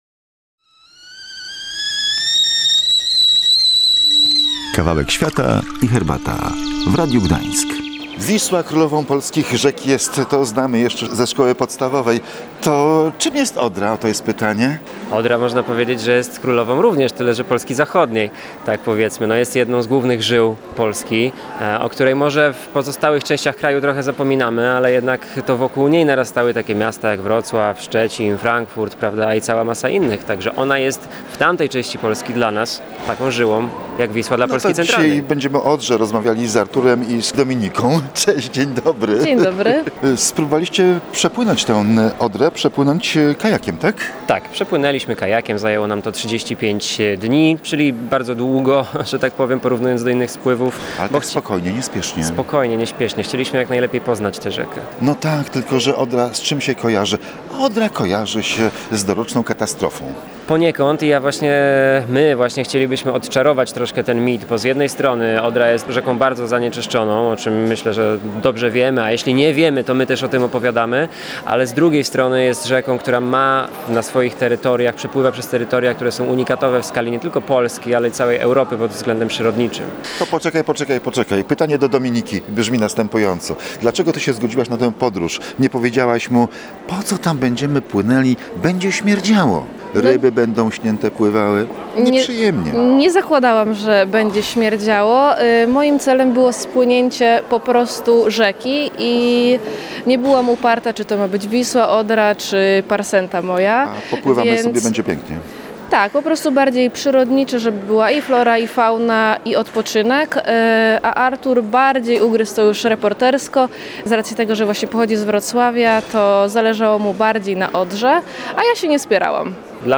Z podróżnikami rozmawiał